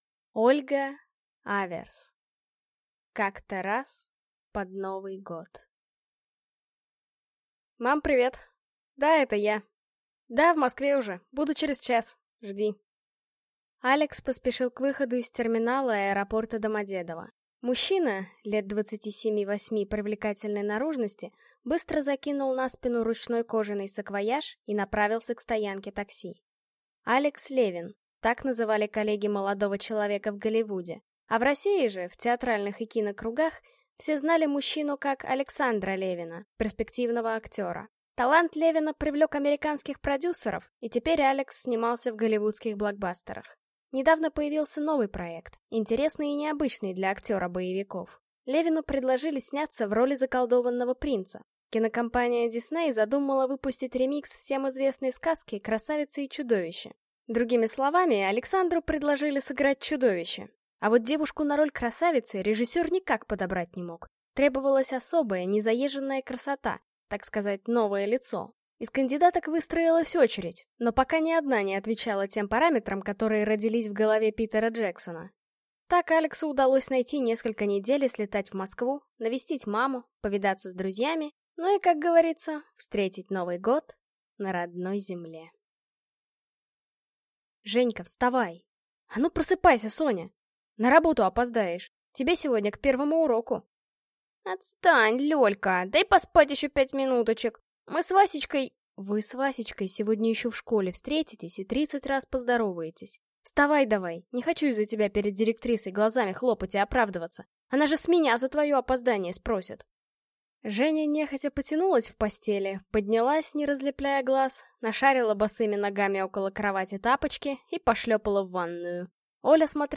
Аудиокнига Как-то раз под Новый год | Библиотека аудиокниг